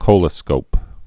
(kōlə-skōp)